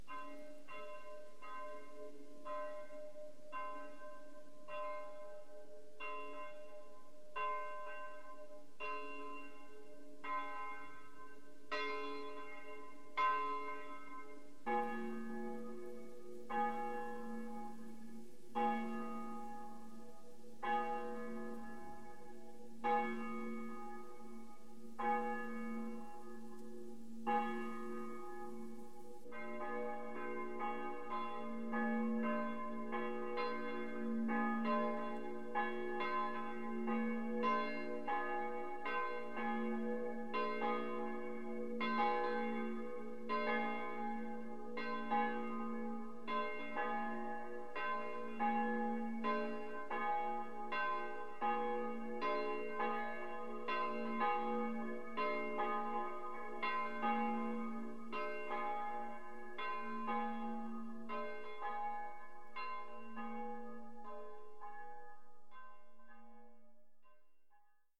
klockor_stavnas.mp3